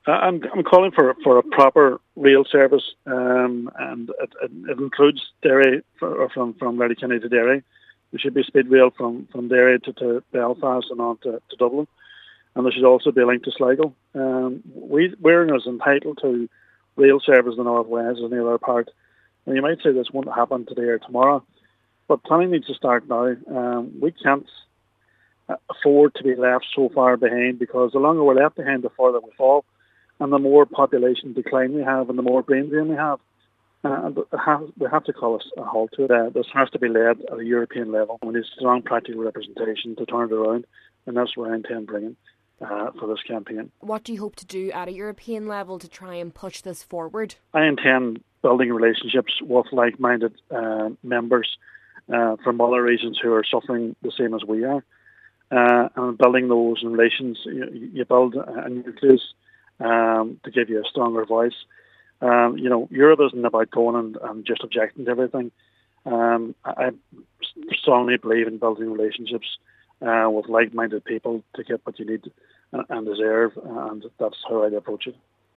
The Senator is one of three Fianna Fáil European candidates, and he says networking at an EU level is key: